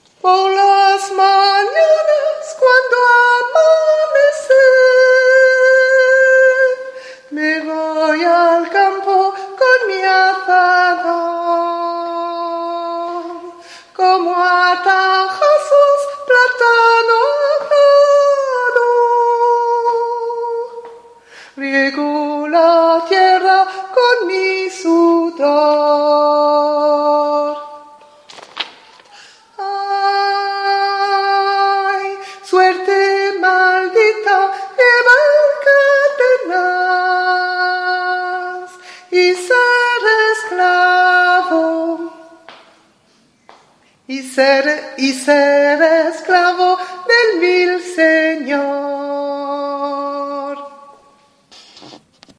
les sons pour apprendre, par vos cheffes préférées